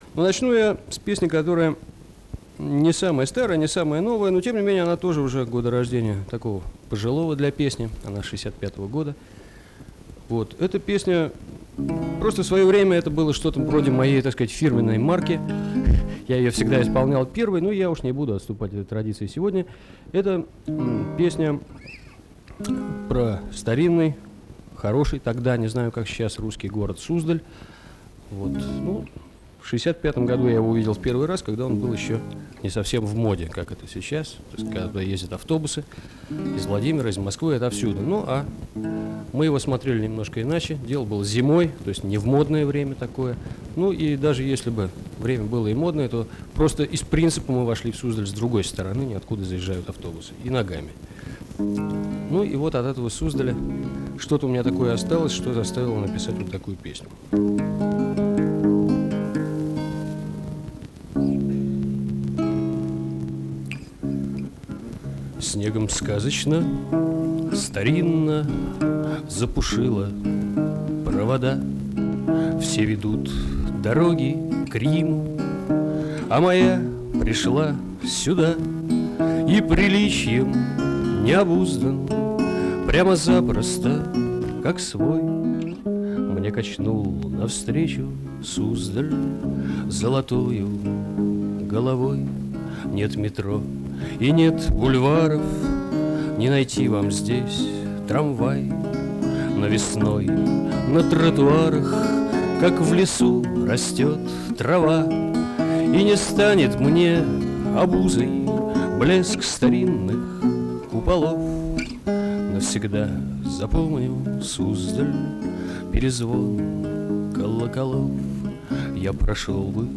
Теперь бы еще кто-нибудь убрал "разговор" перед песней.